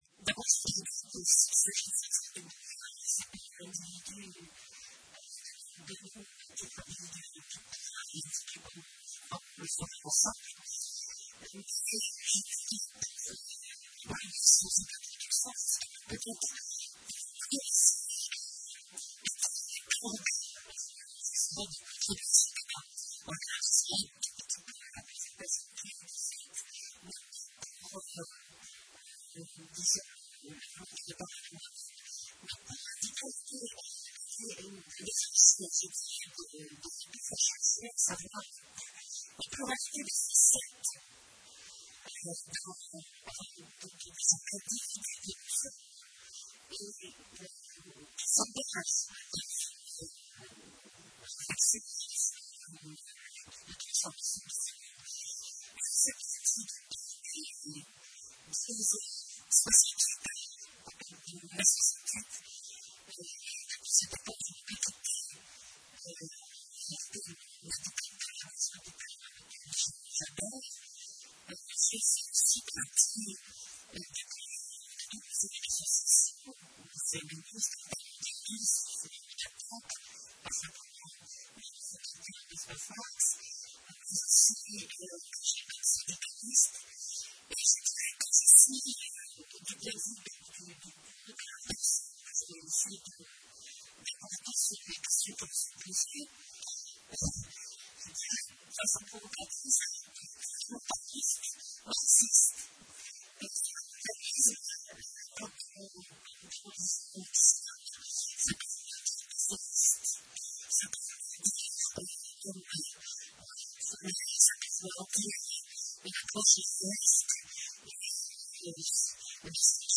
Conférence-débat
L’émission radiophonique (au format mp3)